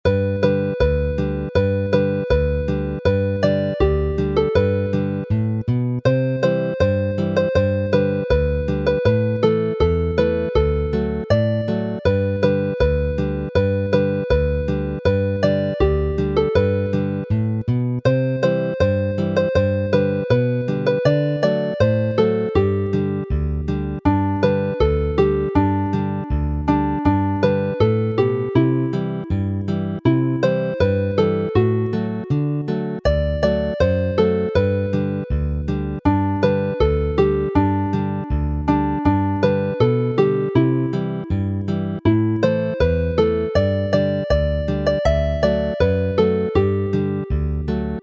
Chwarae'r alaw